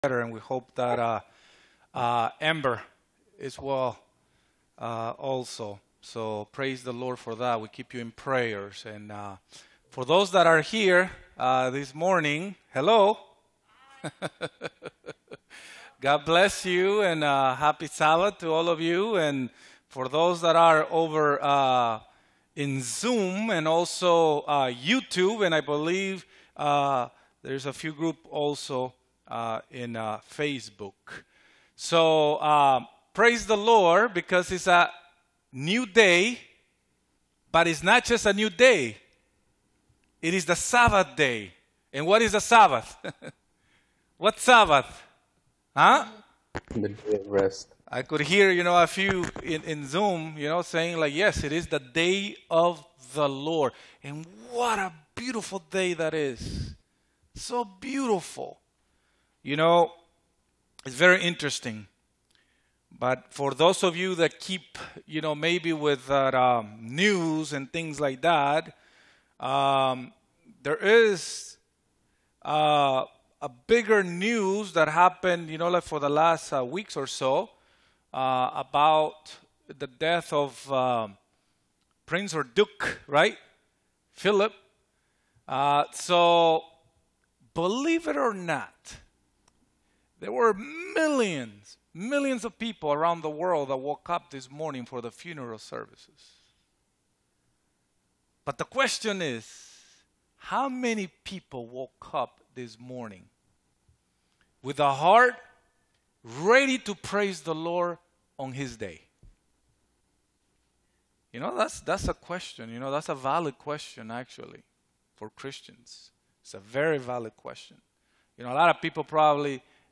Seventh-day Adventist Church